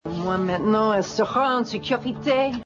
C'est facile a identifier surtout quand on sait d'ou ça vient XD (j'ai du me repasser la vidéo 4 fois avant de comprendre ce que dis la madame).